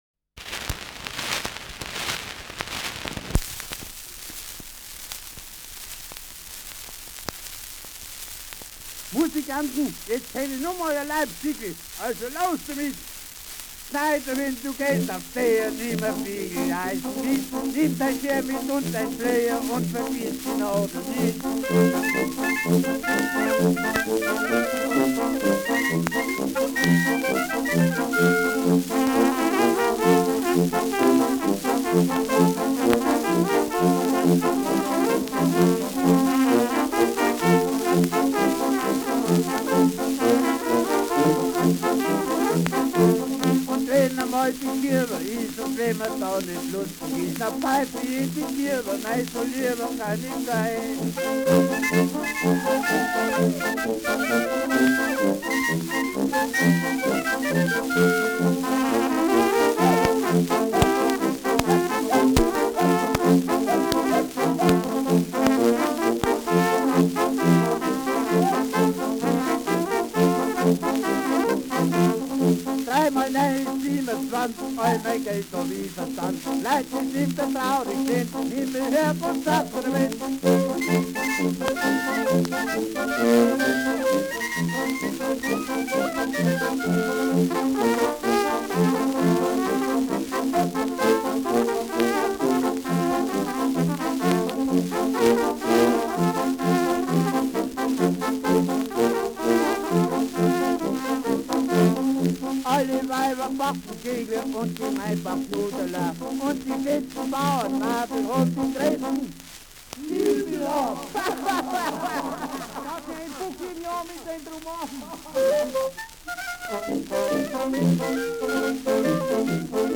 Schellackplatte
Stärkeres Grundrauschen : Gelegentlich leichtes bis starkes Knacken : Sprung im zweiten Drittel
Die Begleitung steigt bald ein. Für die Tanzpaare spielen die Melodie-Instrumente nun den Vierzeiler nach und hängen einen zweiten Teil an.